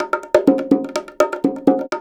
Index of /90_sSampleCDs/Houseworx/02 Percussion Loops